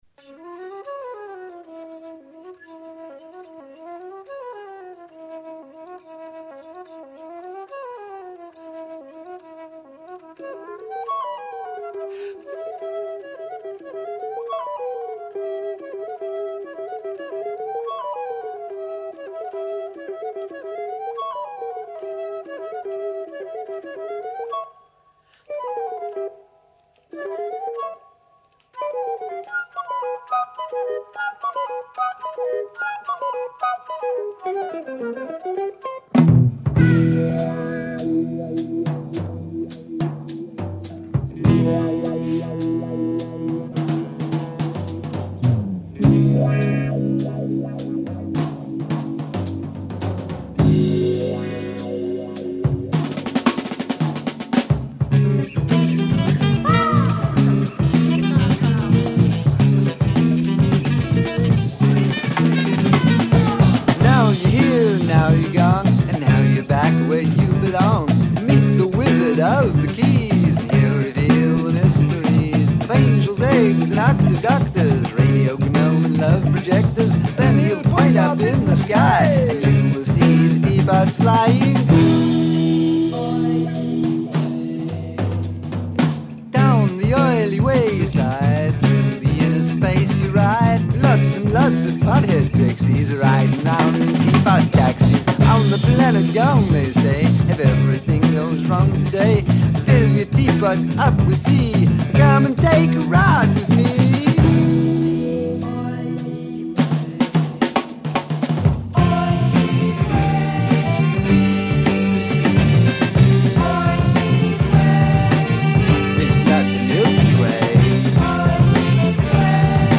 Don’t slip on that saxophone!